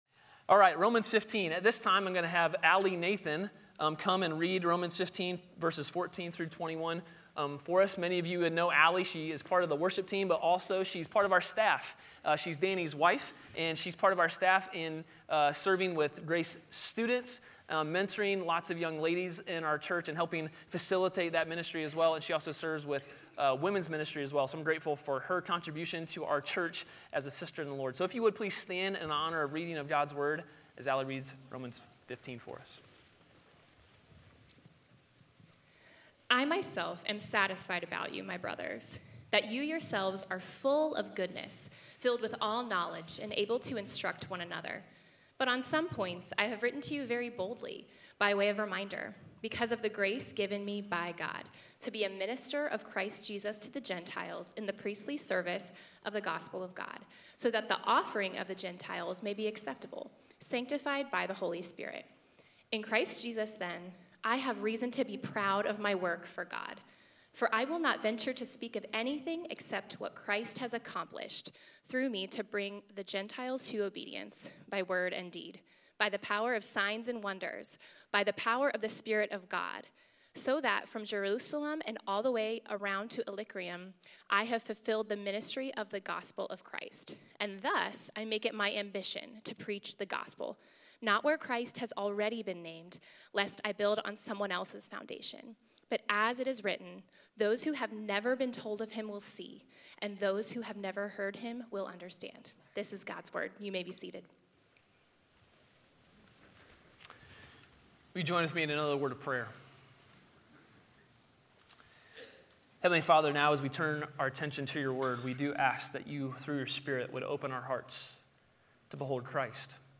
A sermon from the series "The Great Reversal." Jesus endured humiliation so that we could share in His glory.